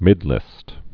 (mĭdlĭst)